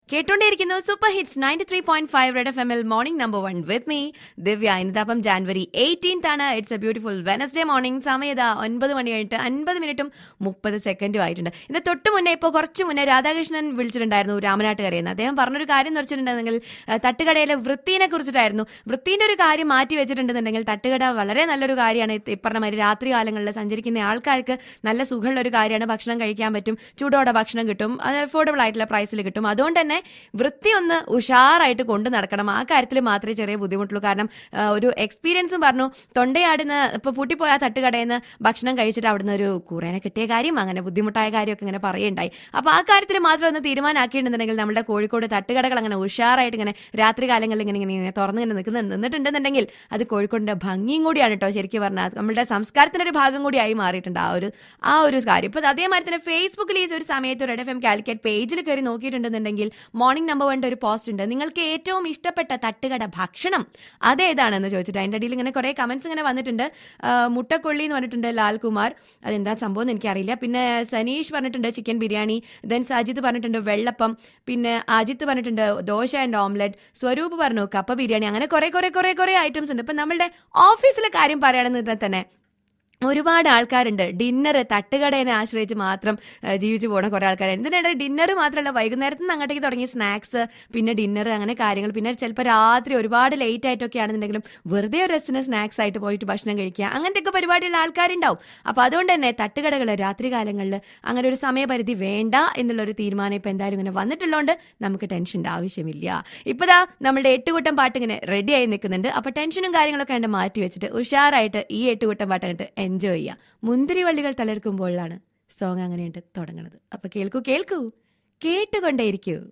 CALLER ON തട്ടുകടകൾക്കു രാത്രികാലങ്ങളിൽ സമയ പരിധി ആവശ്യമുണ്ടോ ??!